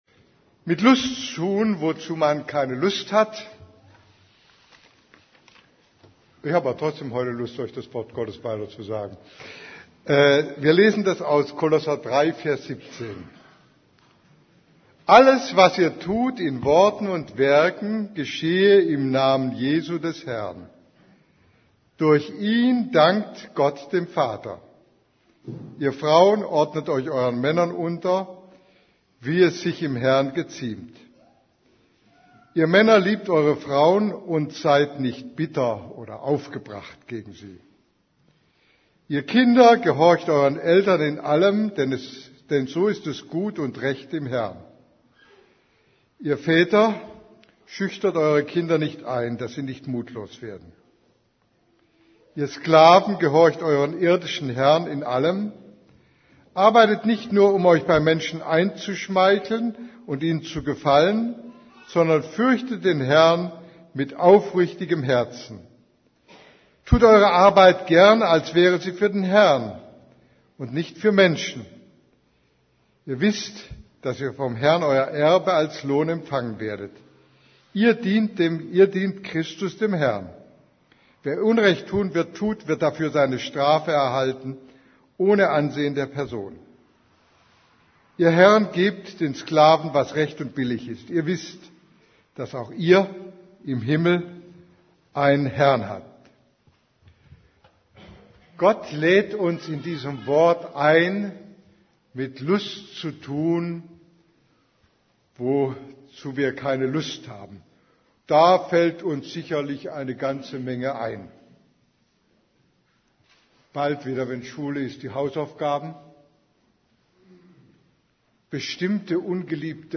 > Übersicht Predigten Mit Lust tun, wozu man keine Lust hat Predigt vom 01.